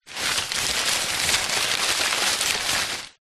Звуки шуршания бумагой